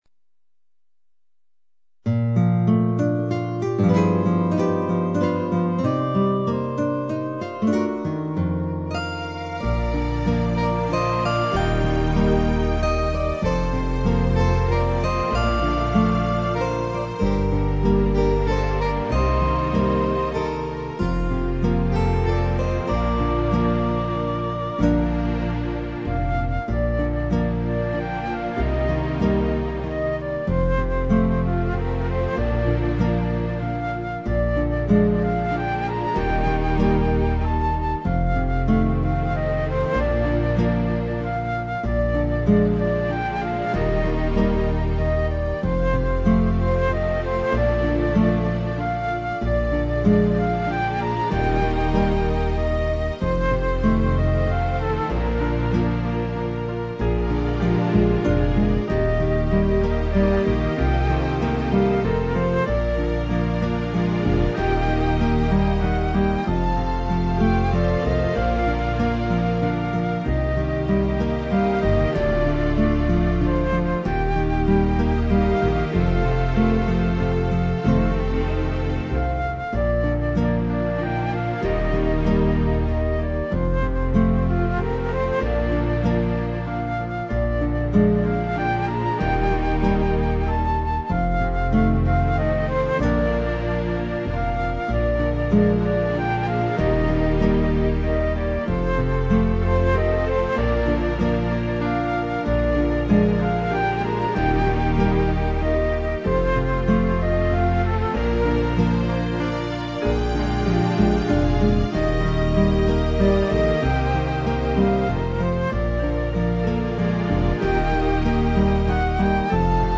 Tone Nam (B)
(Beat + Melody)